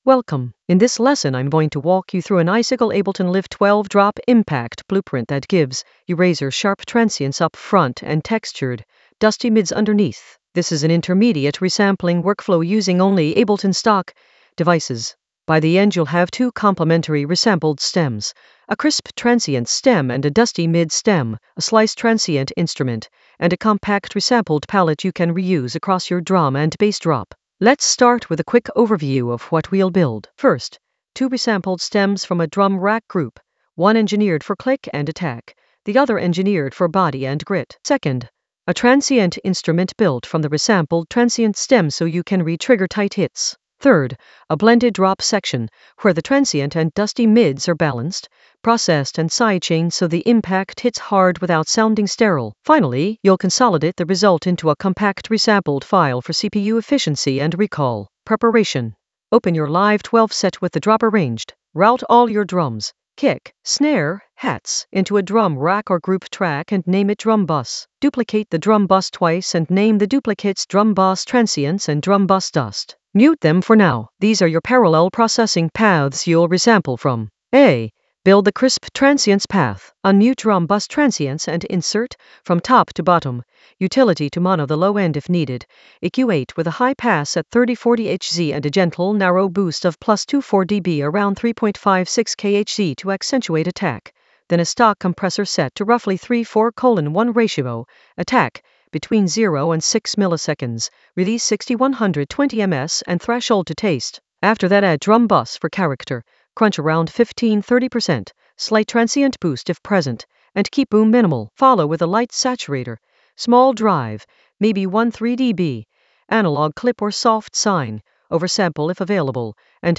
An AI-generated intermediate Ableton lesson focused on Icicle Ableton Live 12 drop impact blueprint with crisp transients and dusty mids in the Resampling area of drum and bass production.
Narrated lesson audio
The voice track includes the tutorial plus extra teacher commentary.